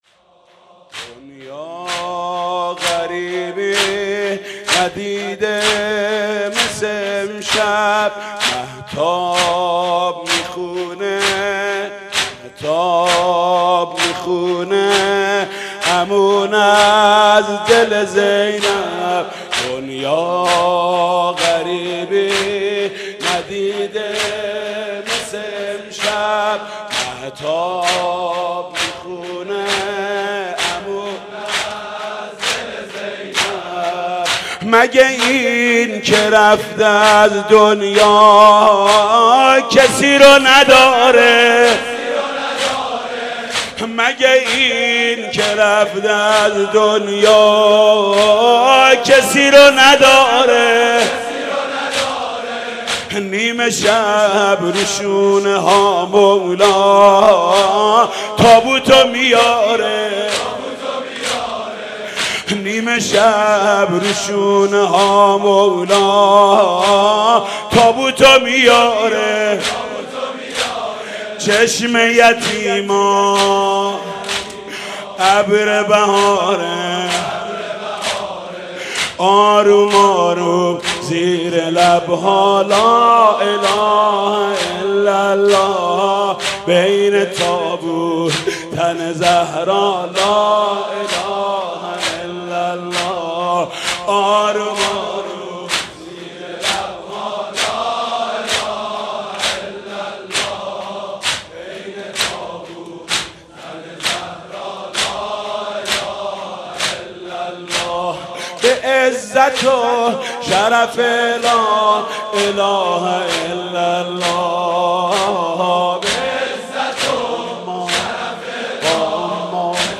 «فاطمیه 1392» زمینه: دنیا غریبی ندیده مثل امشب